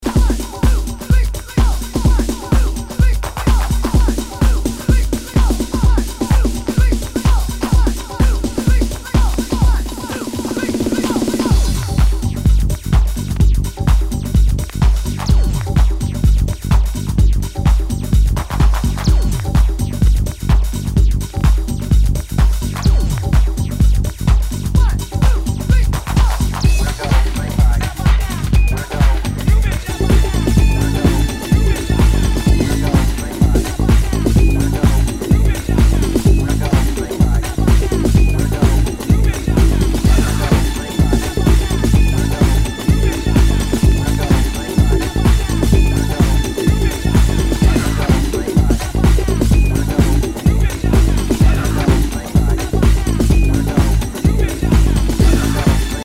HOUSE/TECHNO/ELECTRO
ナイス！ディープ・ハウス！
全体にチリノイズが入ります